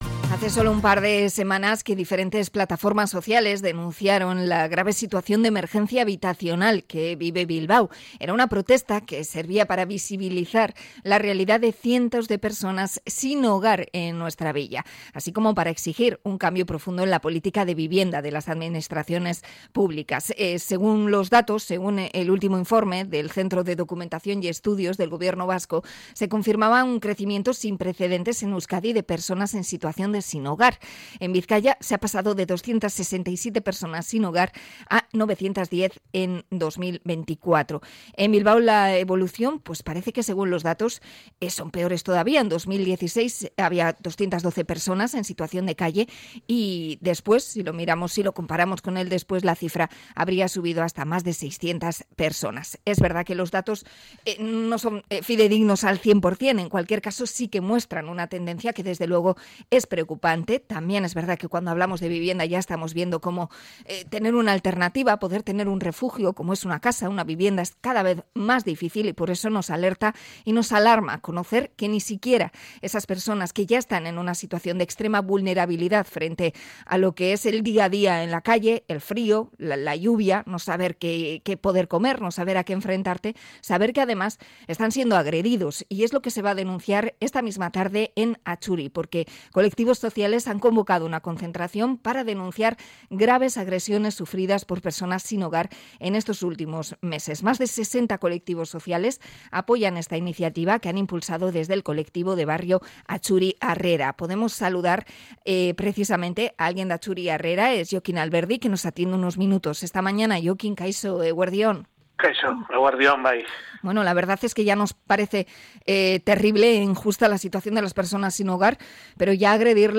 Entrevista a Atxuri Harrera por las agresiones a sinhogar